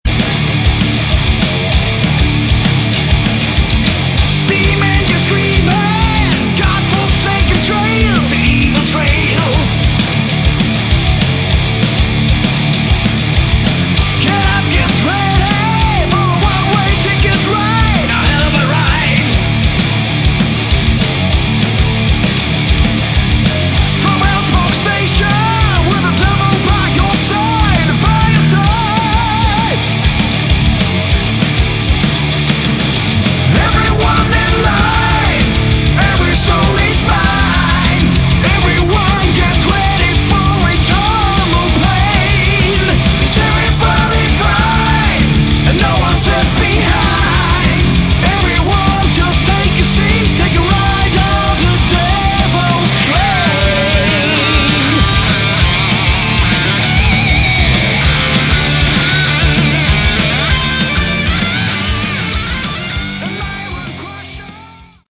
Category: Melodic Metal
vocals
guitars
drums
bass